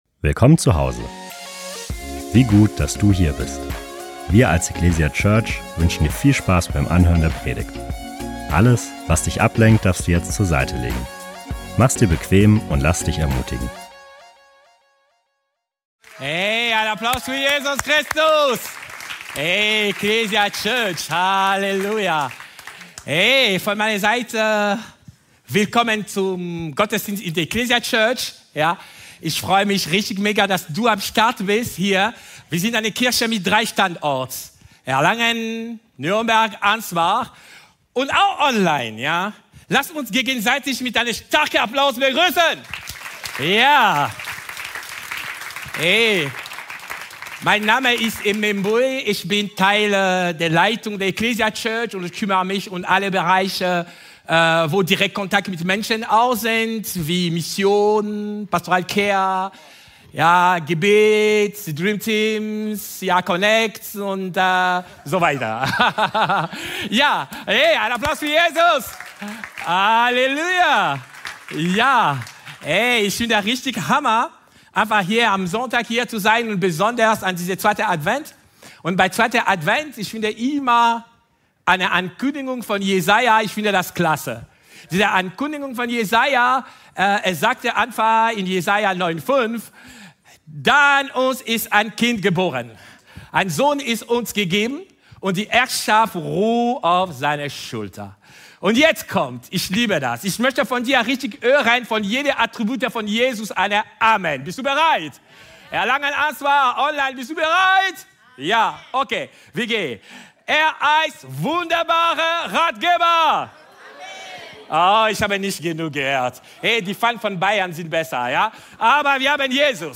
Die Bibelstellen zur Predigt und eine Möglichkeit aktiv mitzuschreiben, findest du in der digitalen Predigtmitschrift.